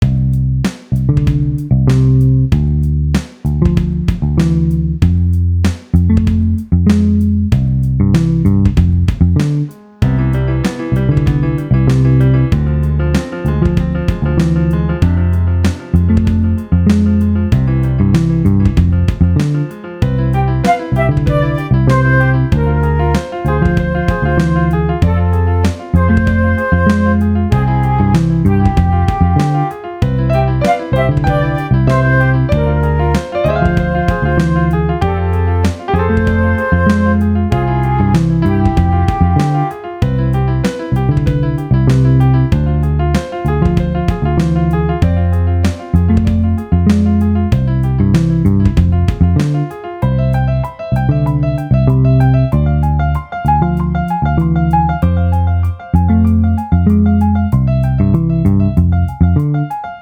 It’s a very short melody that loops continuously.